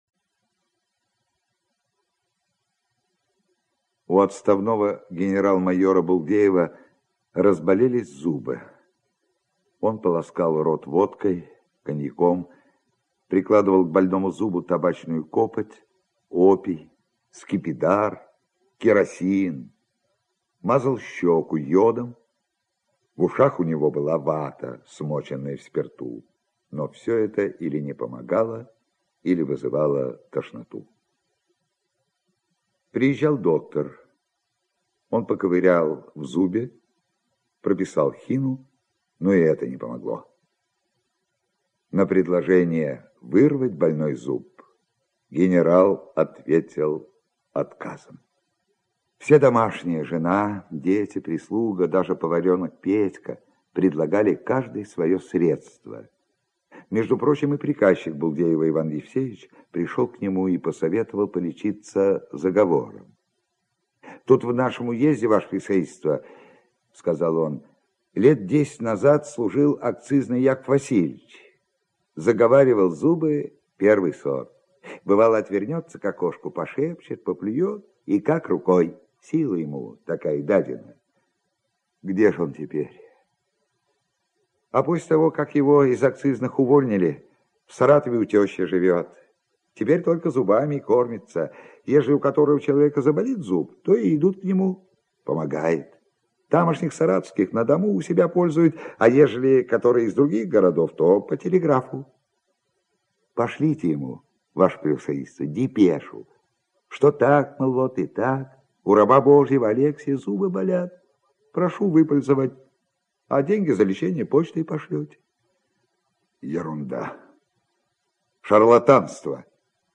Лошадиная фамилия - аудио рассказ Чехова А.П. Рассказ про отставного генерал-майора, который никак не мог решиться вырвать больной зуб.